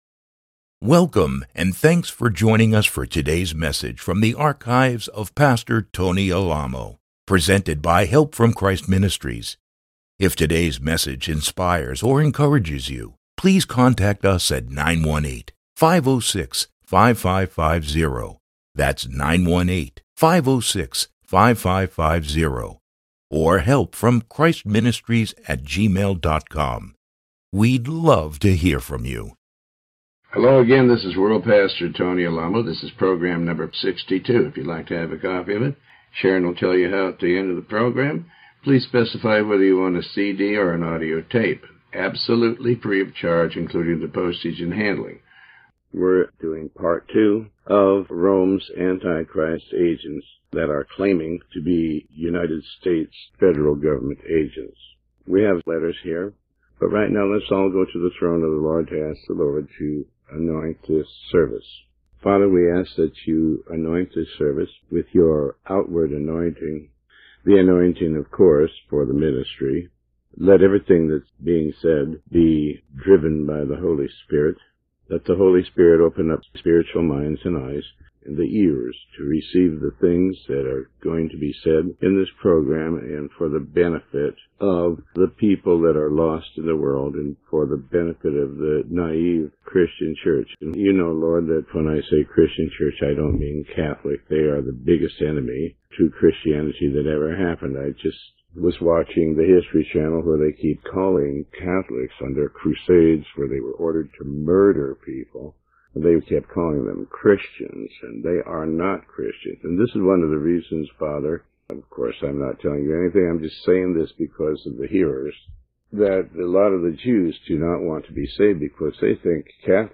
Sermon 62 A